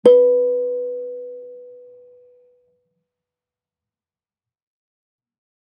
kalimba1_circleskin-B3-ff.wav